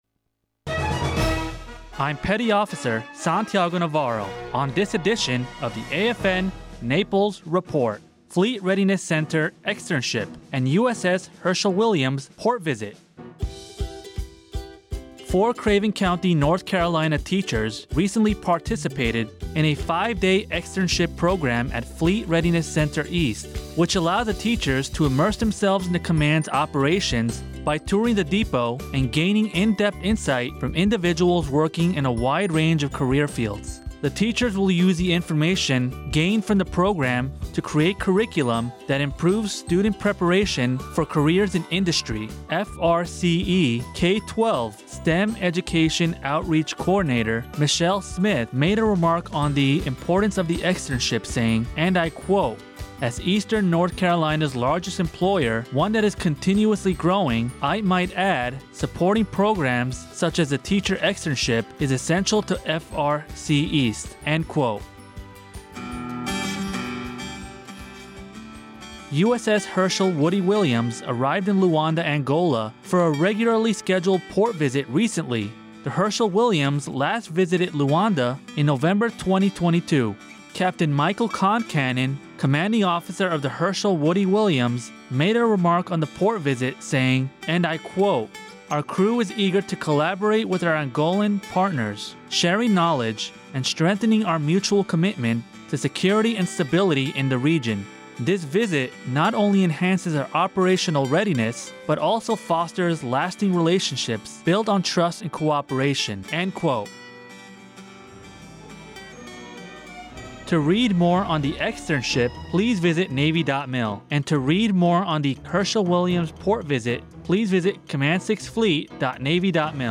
Radio news highlighting a teacher externship with Fleet...